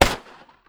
PistolFire1.wav